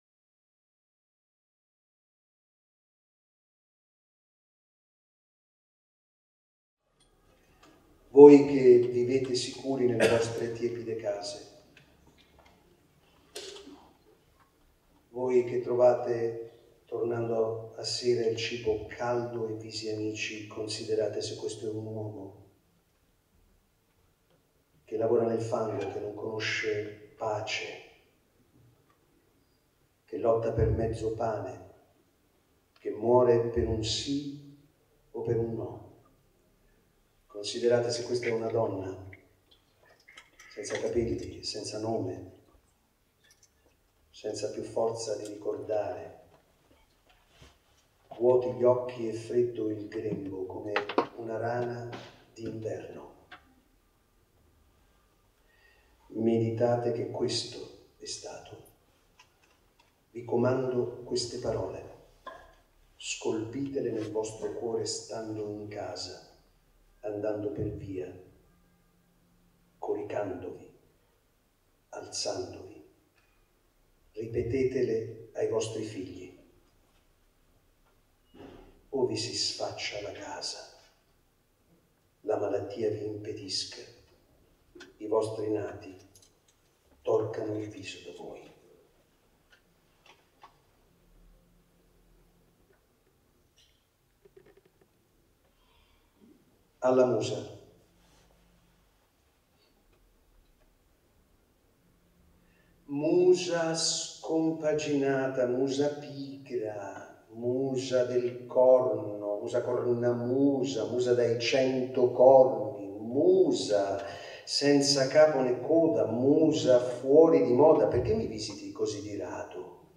Toni Servillo legge Primo Levi
teatro Manzoni 3